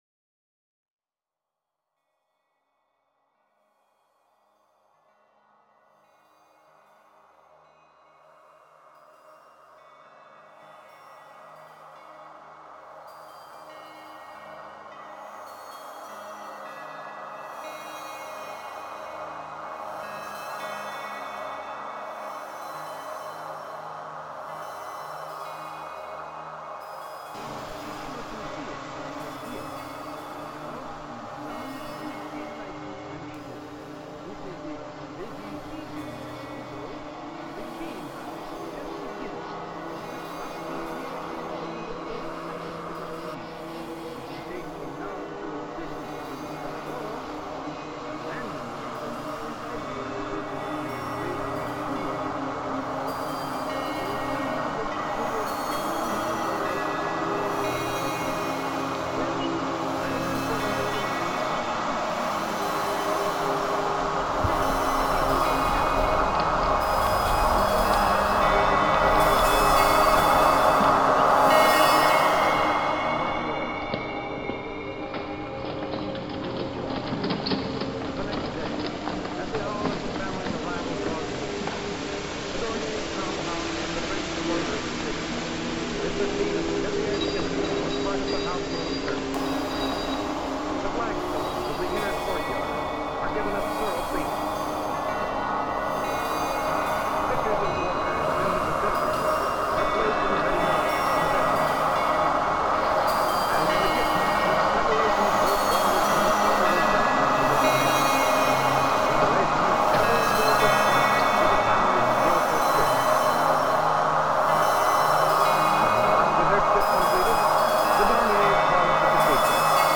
Genre: Ambient/Drone/Field Recordings.